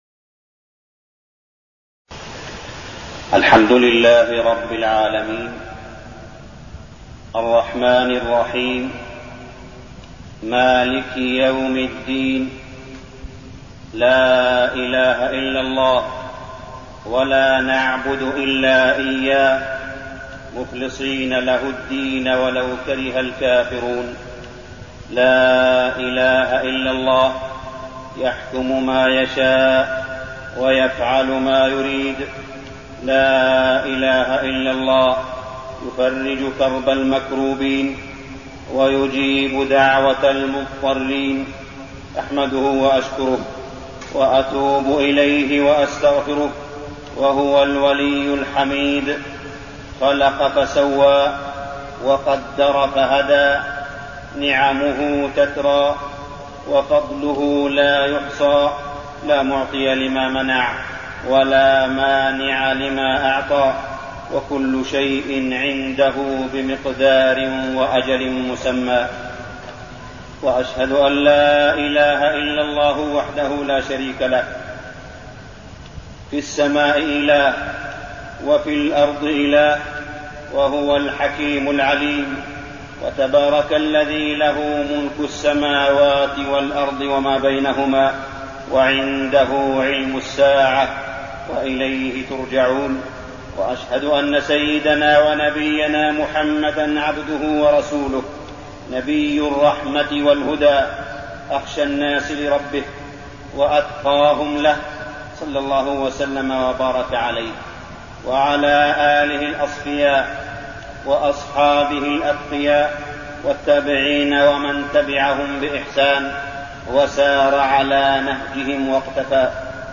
خطبة العيد-دستورنا القرآن
تاريخ النشر ٢٩ رجب ١٤١٣ هـ المكان: المسجد الحرام الشيخ: معالي الشيخ أ.د. صالح بن عبدالله بن حميد معالي الشيخ أ.د. صالح بن عبدالله بن حميد خطبة العيد-دستورنا القرآن